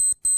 Lowered the volume on the time announce beep.
Announce.wav